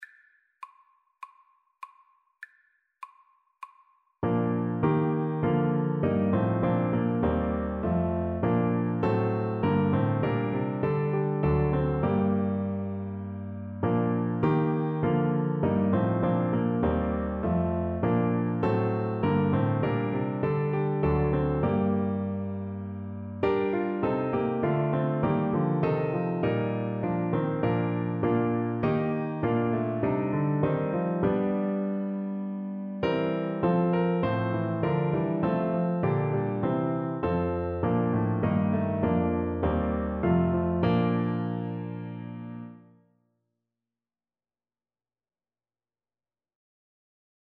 Classical Hassler, Hans O Sacred Head, Now Wounded Trombone version
Christian Christian Trombone Sheet Music O Sacred Head, Now Wounded
Trombone
4/4 (View more 4/4 Music)
Bb major (Sounding Pitch) (View more Bb major Music for Trombone )
Classical (View more Classical Trombone Music)